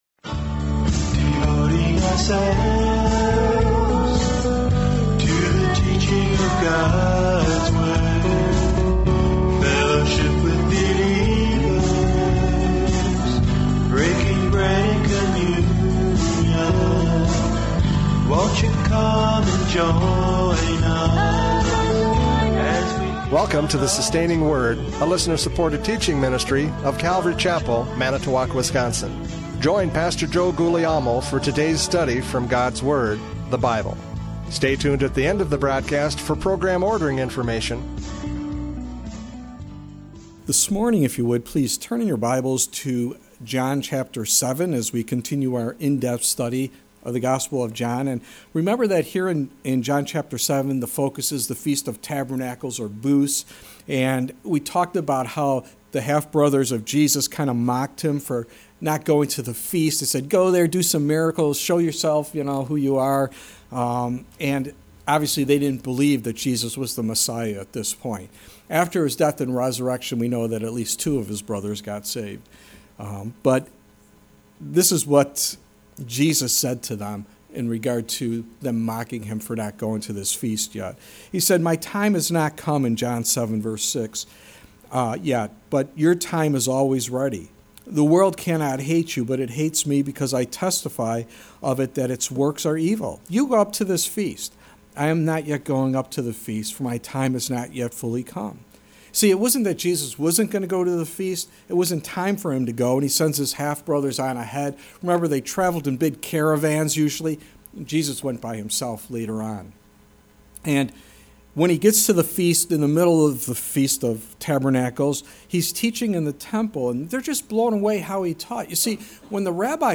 John 7:37-52 Service Type: Radio Programs « John 7:14-36 Debating Jesus!